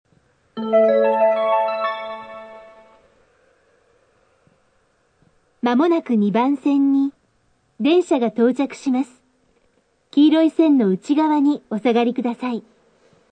◆中間駅タイプ（上本郷にて）
ほとんどの駅でTOAの箱形が使用されているが、音が隠る傾向があり新鎌ヶ谷と比較すると、かなり劣る。
スピーカー：TOA箱形
１番線 接近メロディー+放送（男性）